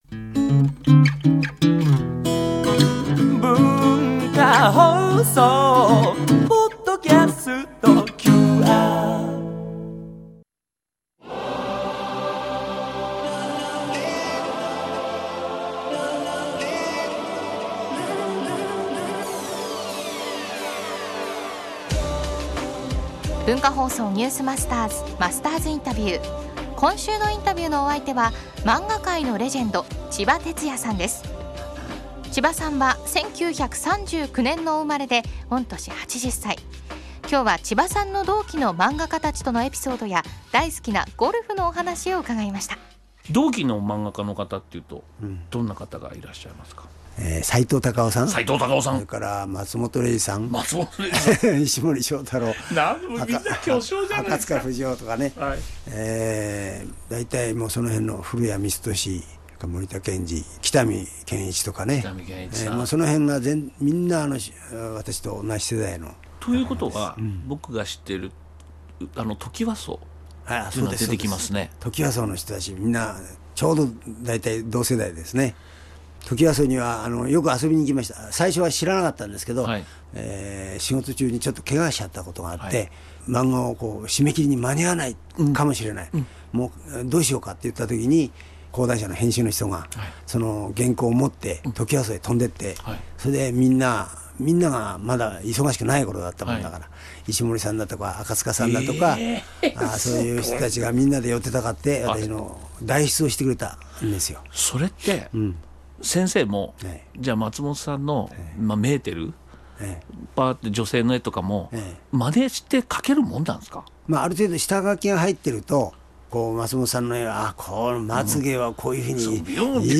The News Masters TOKYO 「マスターズインタビュー」漫画界のレジェンド ちばてつやさん（3日目）
今週のインタビューのお相手は漫画界のレジェンド　ちばてつやさんです。
（月）～（金）AM7：00～9：00　文化放送にて生放送！